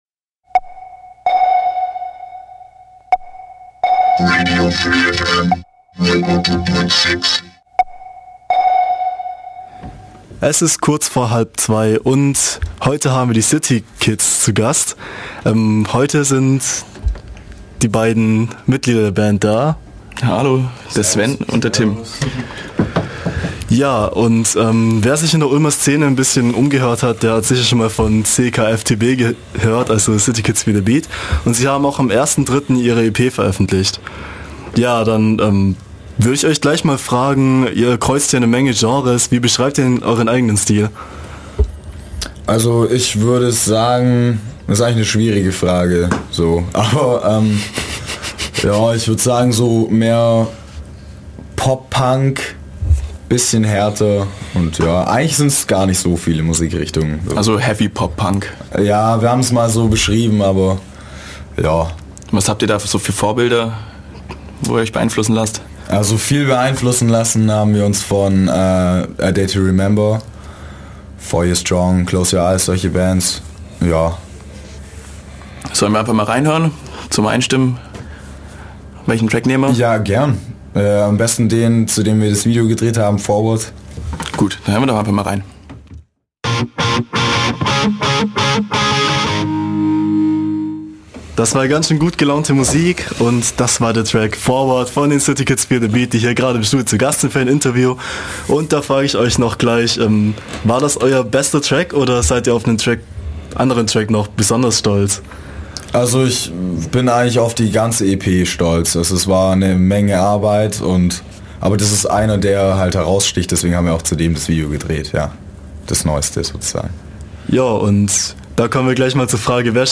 Interview mit City Kids Feel The Beat
Die Münchner Band "City Kids Feel The Beat" war vor ihrem Auftritt im Schilli am Samtag den 07.03. zu Gast in der Ulmer Freiheit.